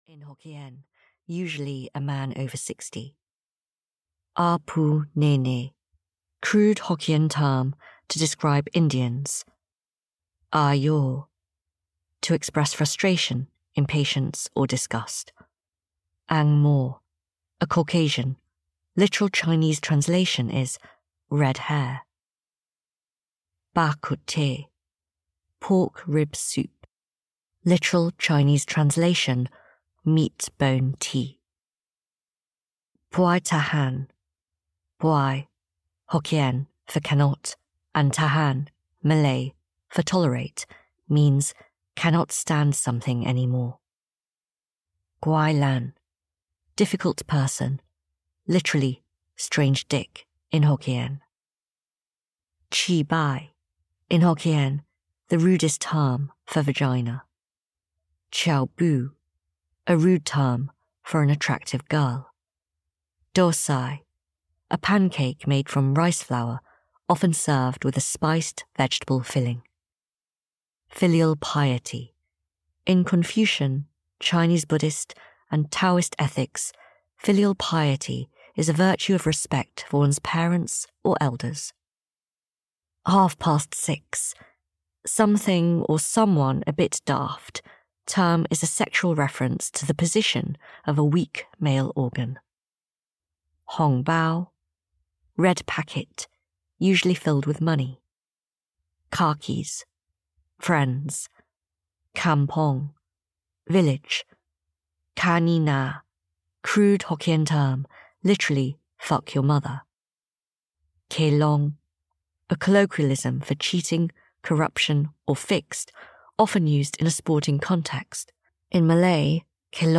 Bloody Foreigners (EN) audiokniha
Ukázka z knihy